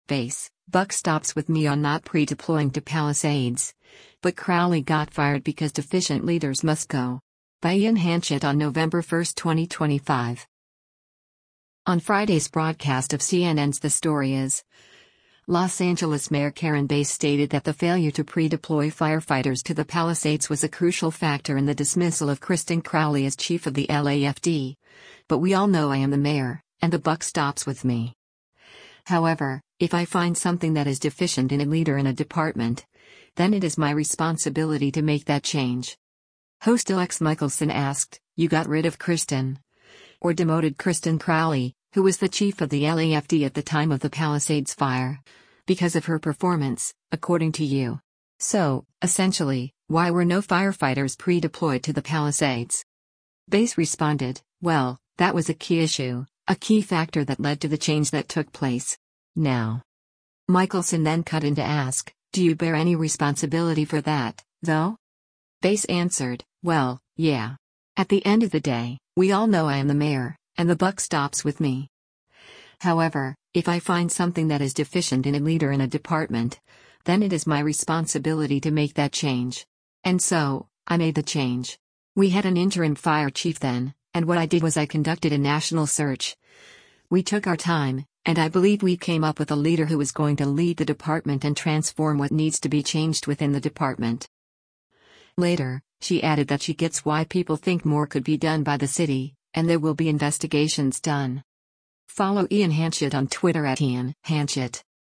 On Friday’s broadcast of CNN’s “The Story Is,” Los Angeles Mayor Karen Bass stated that the failure to pre-deploy firefighters to the Palisades was a crucial factor in the dismissal of Kristin Crowley as chief of the LAFD, but “we all know I am the Mayor, and the buck stops with me. However, if I find something that is deficient in a leader in a department, then it is my responsibility to make that change.”